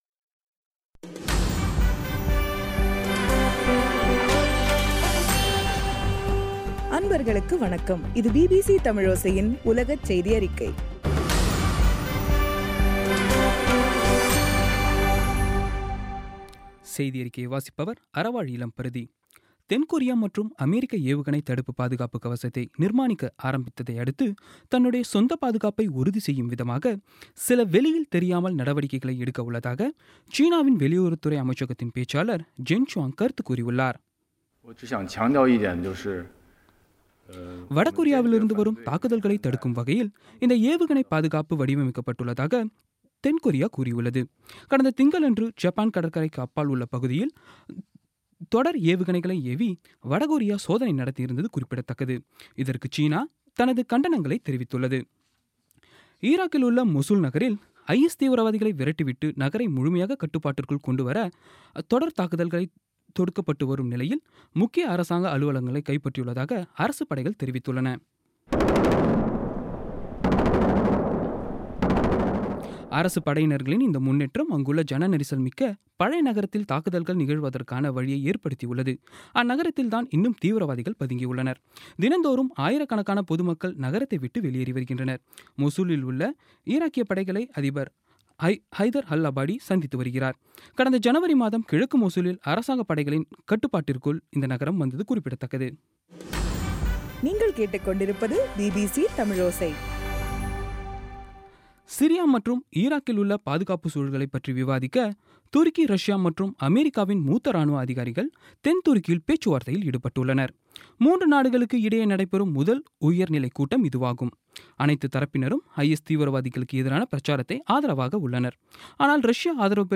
பிபிசி தமிழோசை செய்தியறிக்கை (07/03/17)